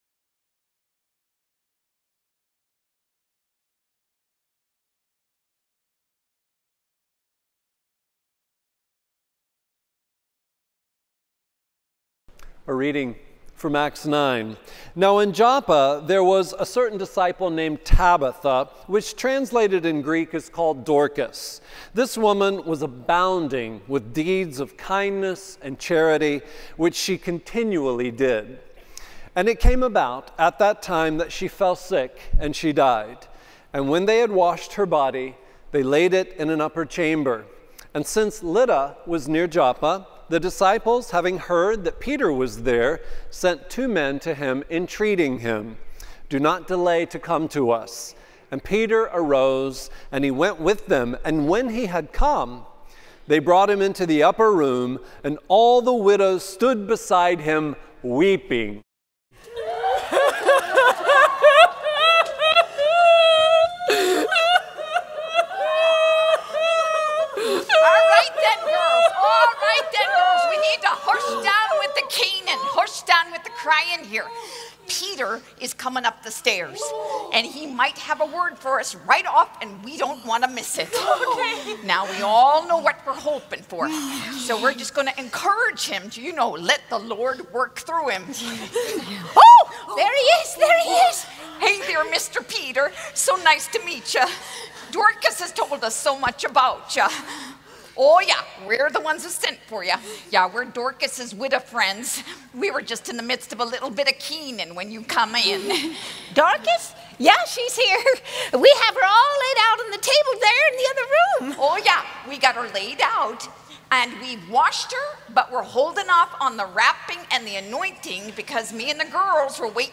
Message 4 (Drama): Dorcas
R17 - Drama 04 - Dorcas.MP3